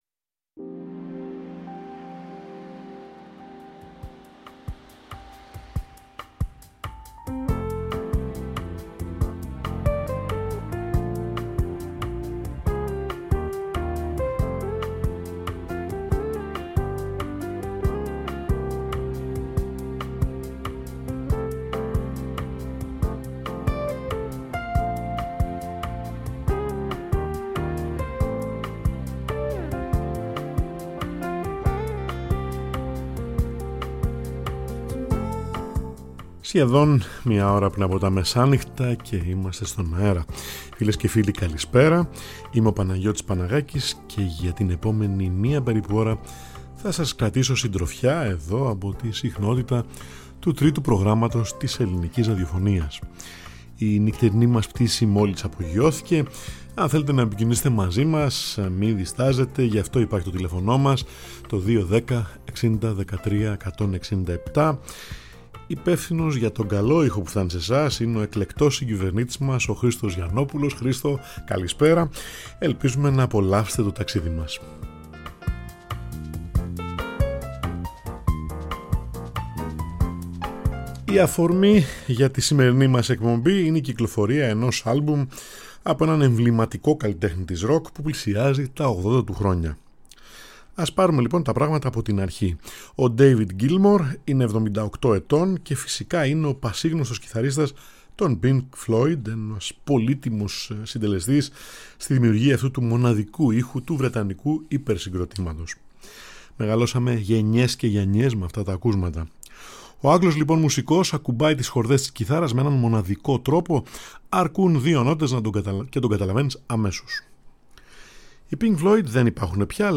H «Νυχτερινή Πτήση» απογειώνεται κάθε Τρίτη & Πέμπτη μία ώρα πριν από τα μεσάνυχτα, στο Τρίτο Πρόγραμμα 90,9 & 95,6 της Ελληνικής Ραδιοφωνίας